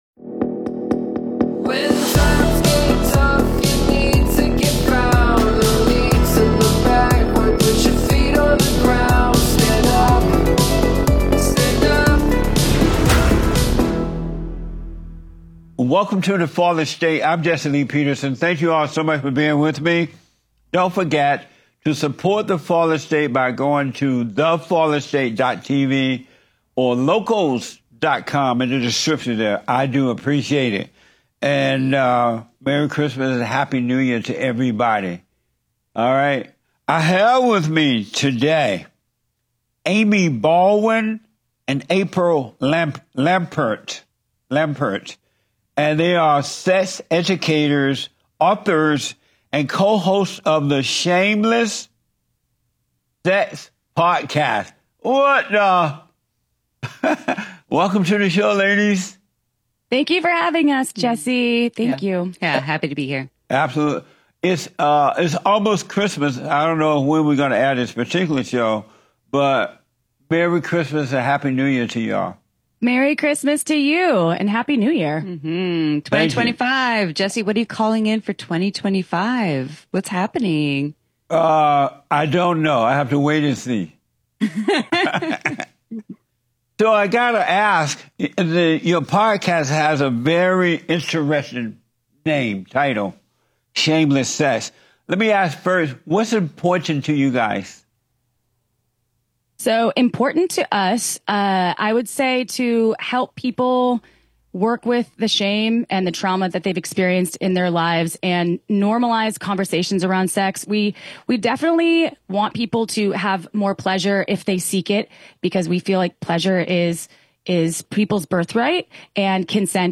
The Fallen State TV is a weekly web series hosted by Jesse Lee Peterson. The program covers topics that no other show dares to touch in pursuit of truth and enlightenment. The program features fascinating and thought-provoking guests who discuss and debate faith, culture, human nature, politics, and societal ills in an open, honest and entertaining style.